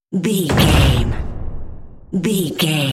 Cinematic drum hit trailer
Sound Effects
Epic / Action
In-crescendo
Atonal
heavy
intense
dark
aggressive
hits